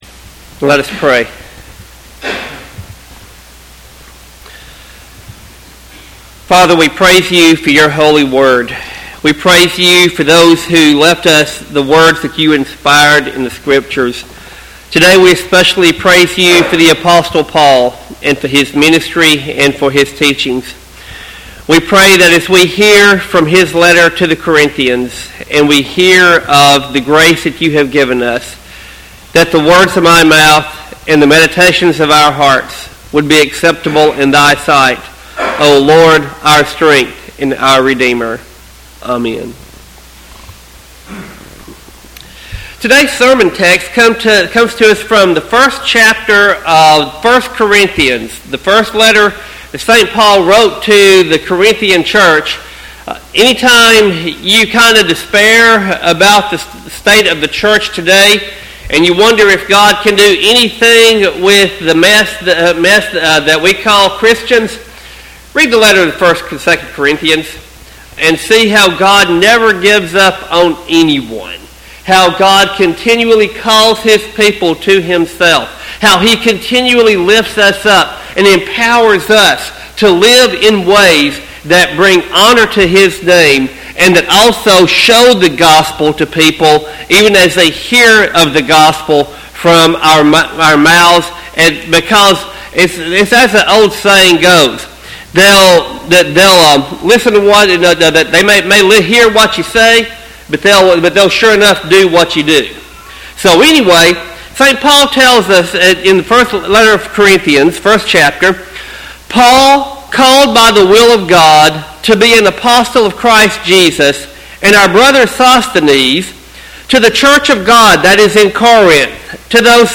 Sermon text: 1 Corinthians 1:1-9.